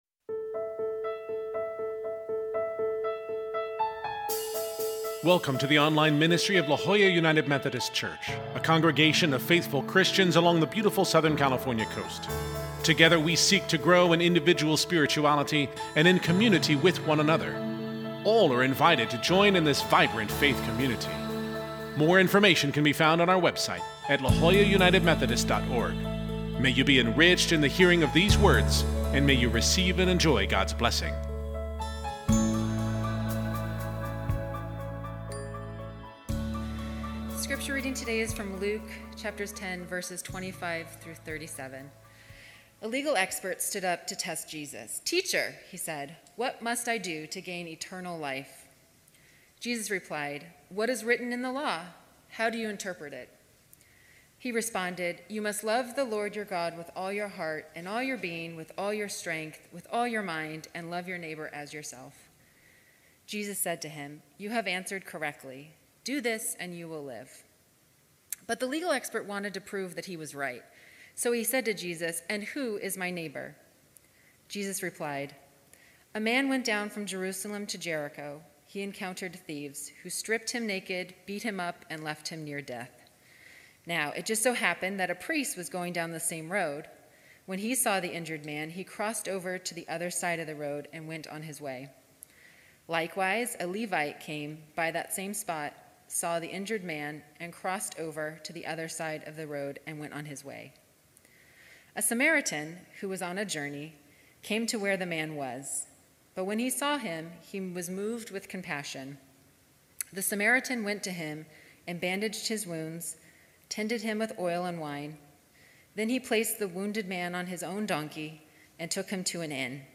This Sunday we continue our sermon series on the parables of Jesus.